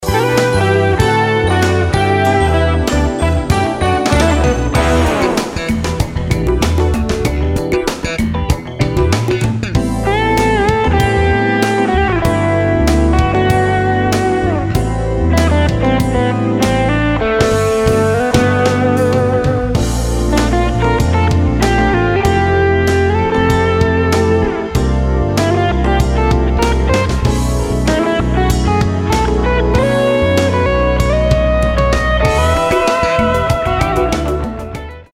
saxophones
guitars, keyboards and harmonicas
world-beat rhythms and sophisticated jazz arrangements
coastal smooth sound